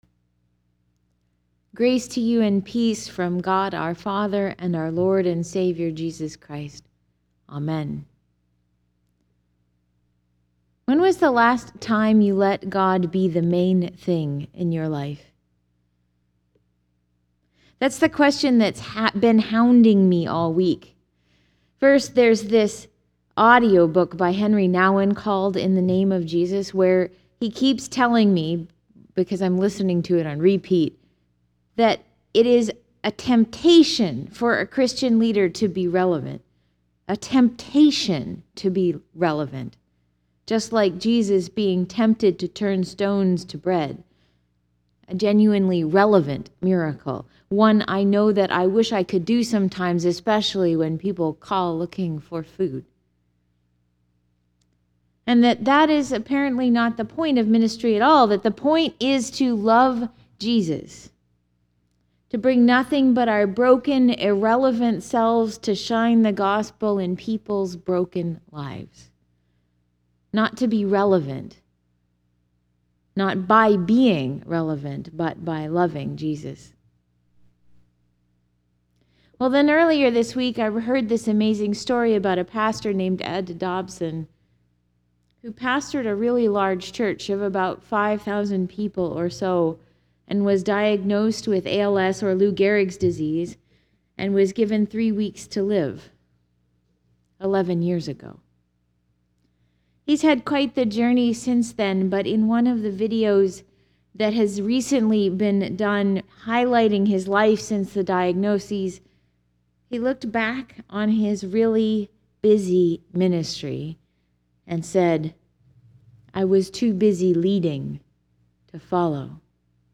Links to things I mention in this sermon: